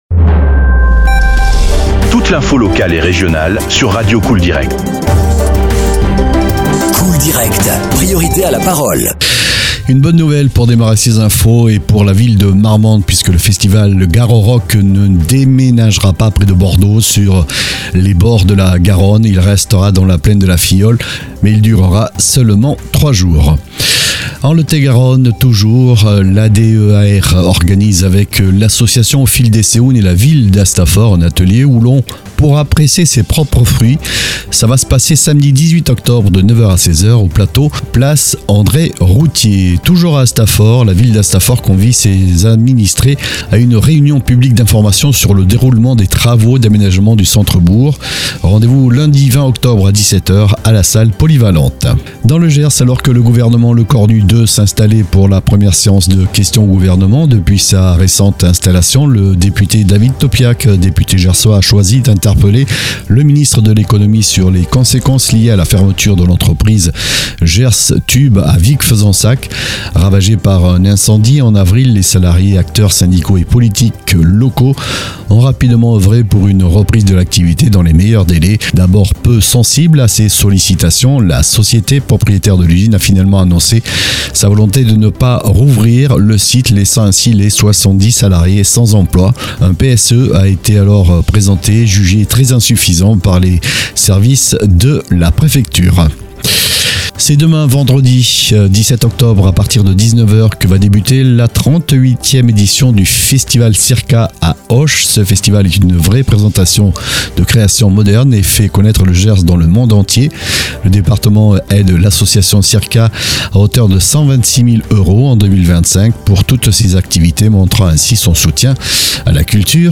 Flash infos 16/10/2024